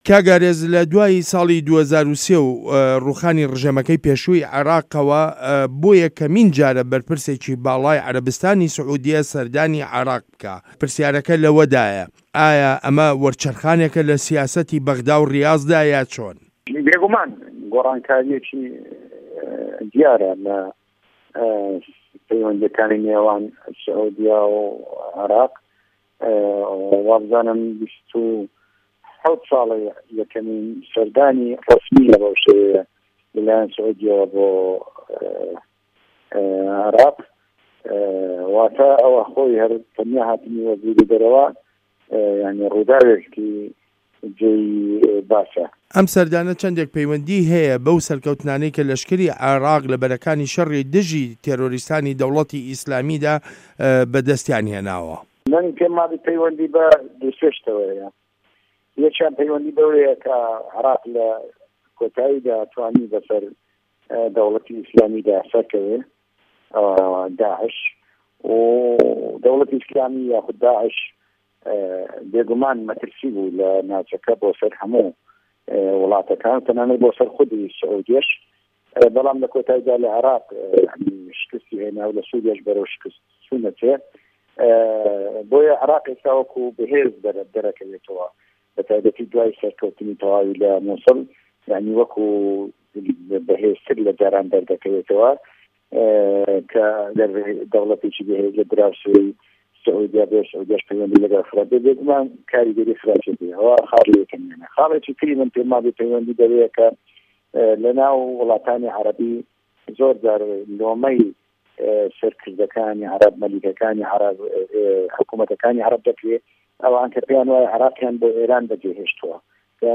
وتووێژ لەگەڵ ئارێز عەبدوڵا